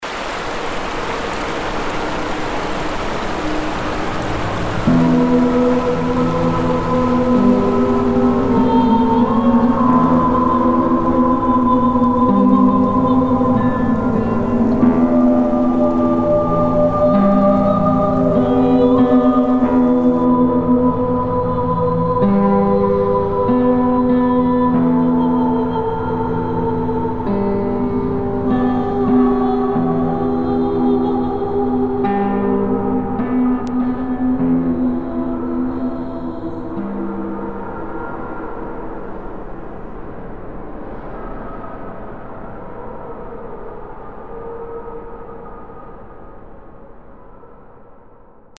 • Жанр: Фолк
Instrumental